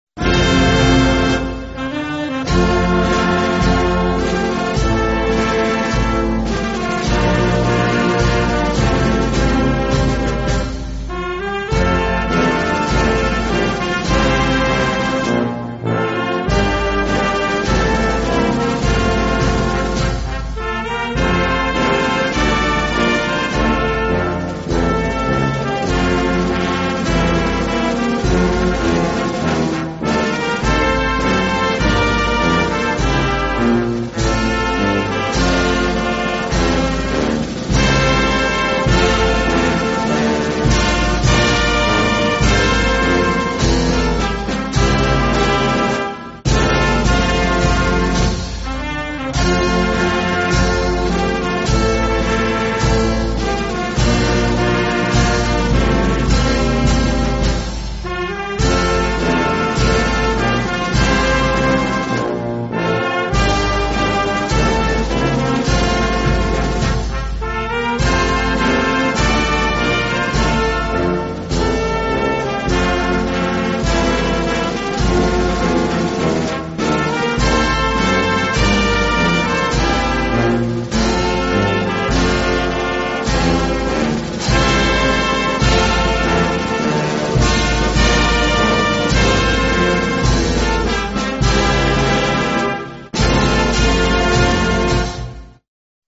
1-gimn-tin-quan-ca-quc-ca-vit-nam-nhc-khong-li.mp3